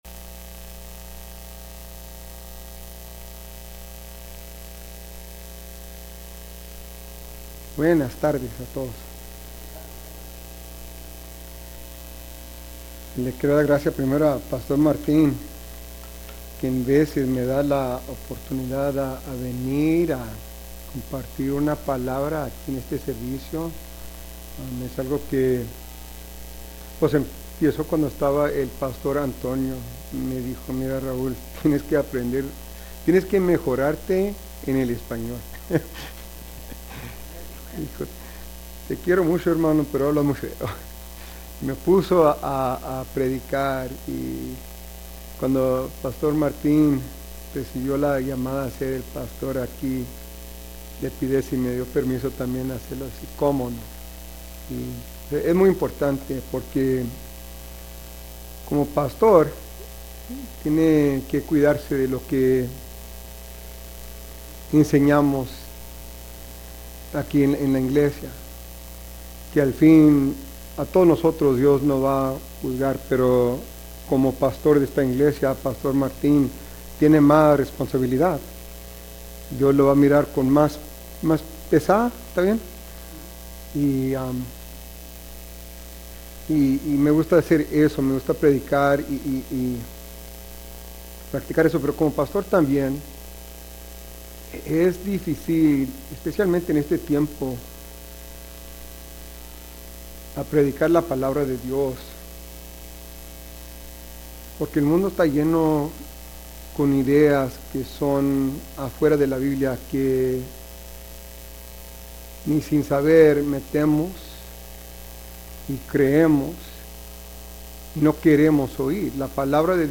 Sermons | Mercy Springs Church of the Nazarene
Acompáñenos a nuestro servicio dominical y adoremos a nuestro Dios vivo, nuestro Señor y Salvador.